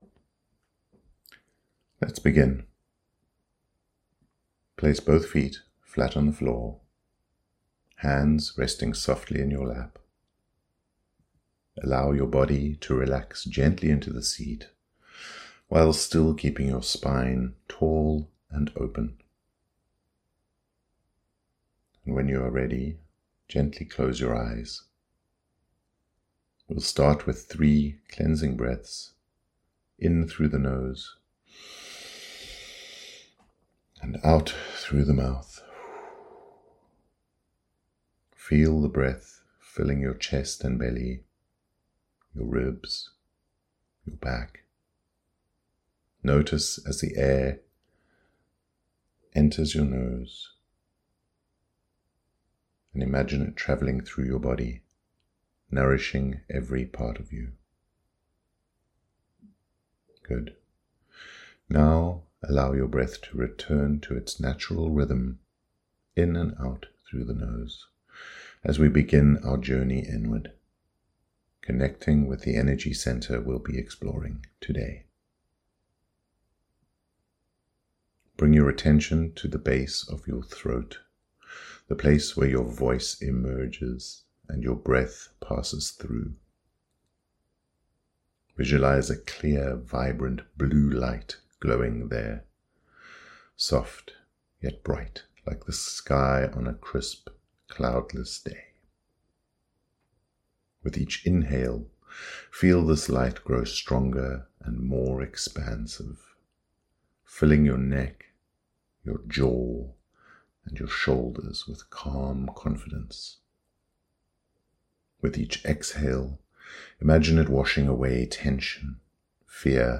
Throat Chakra Meditation
CH05-meditation.mp3